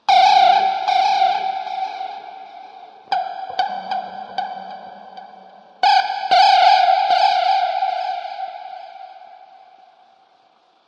恐怖的声音 " 深度敲击或滴水03
描述：这次的滴水声或吱吱声更快。
用原声吉他和各种延迟效果创建。
Tag: 爬行 爬行 哭了 可怕 闹鬼 吉他 恐怖 SFX 怪物 怪物 恐怖 木材 延迟 咆哮 吱吱 环境 回声 昆虫 点击